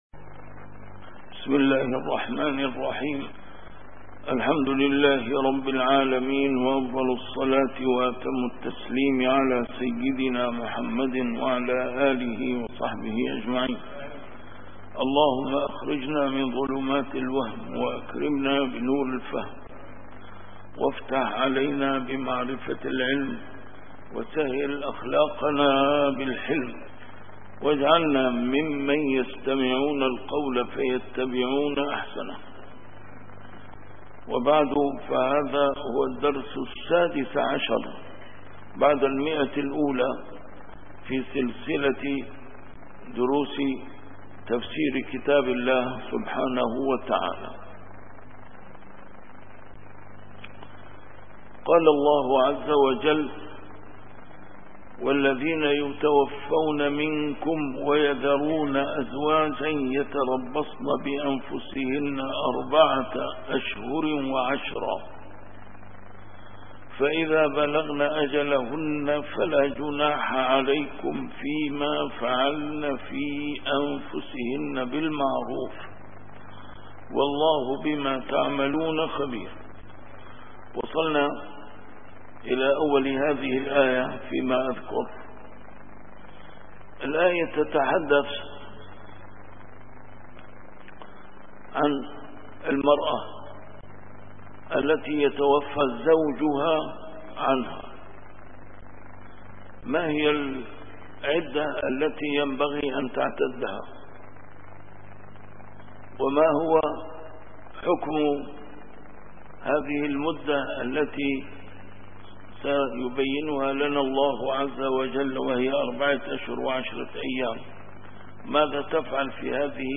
A MARTYR SCHOLAR: IMAM MUHAMMAD SAEED RAMADAN AL-BOUTI - الدروس العلمية - تفسير القرآن الكريم - تفسير القرآن الكريم / الدرس السادس عشر بعد المائة: سورة البقرة: الآية 234-235